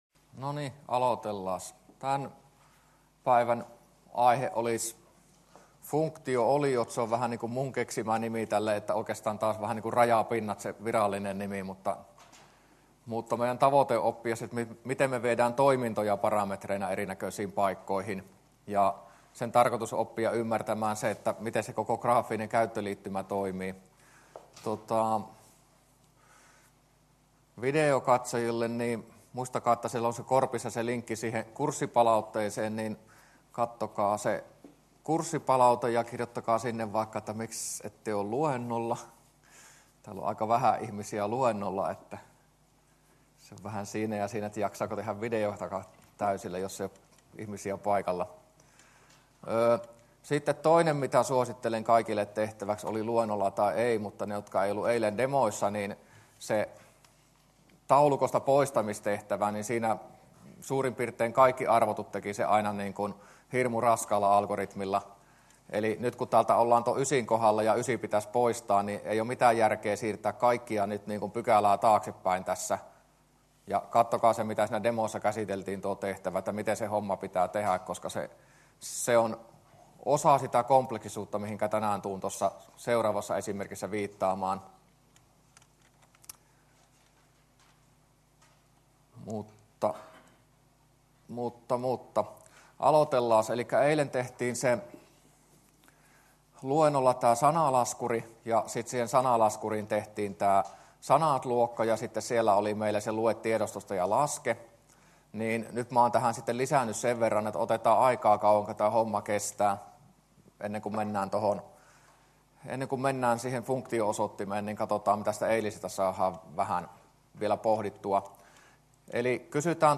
luento20a